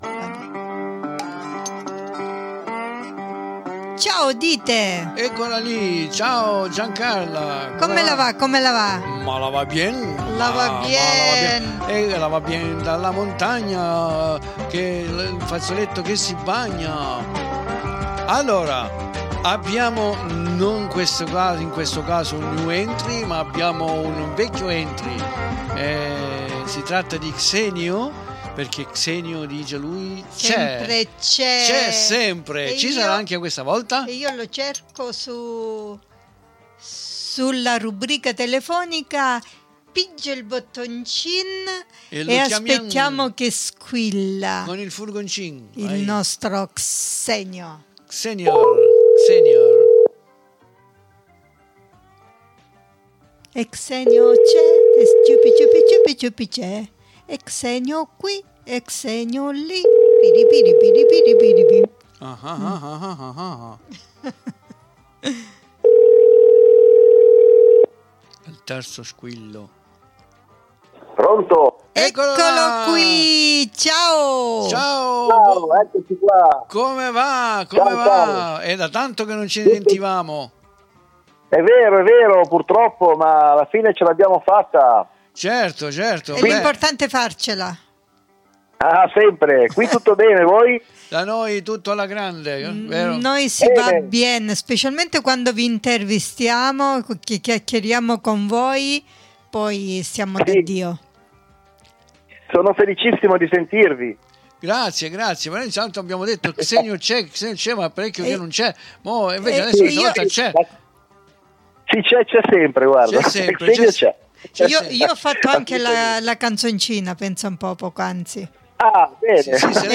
IL RESTO LO ASCOLTIAMO DA LUI, IN QUESTA INTERVISTA CONDIVISA QUI IN DESCRIZIONE.